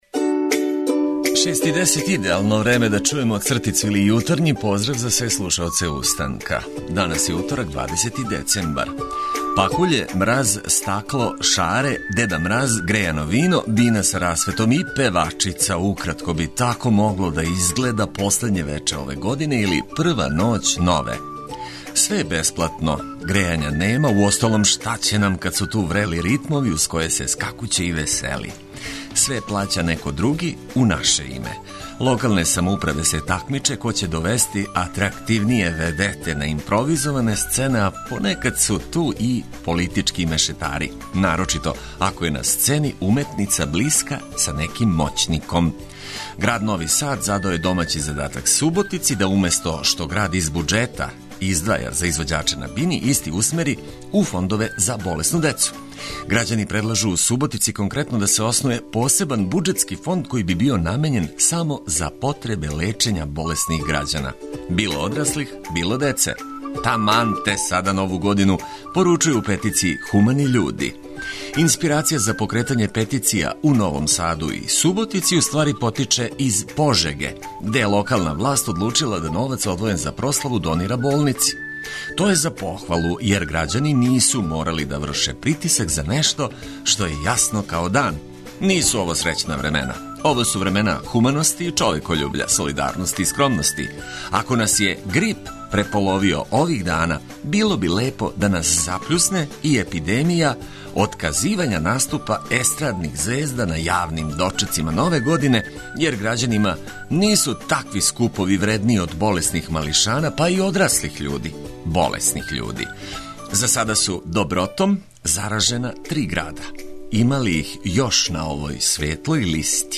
Током јутра важне информације за све који нас ослушкују прошаране ведром музиком за лакше устајање из кревета.